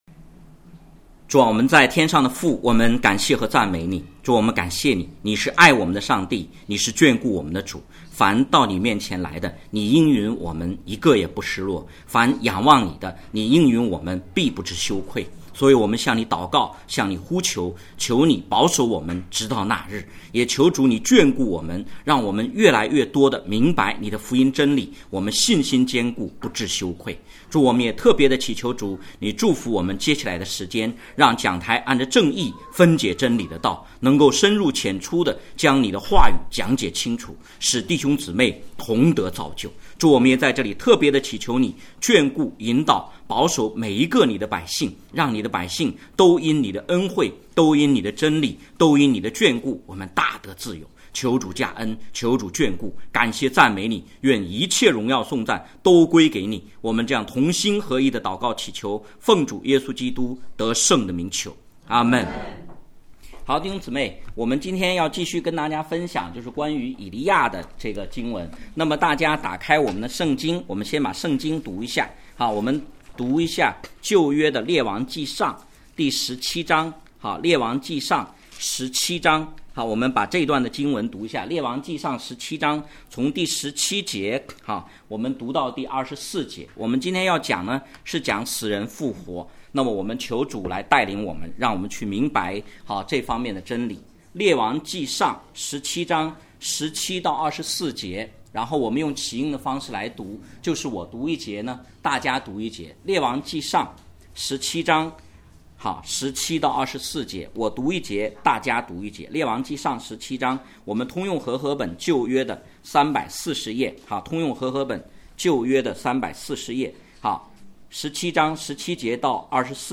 讲道录音 点击音频媒体前面的小三角“►”就可以播放 ： https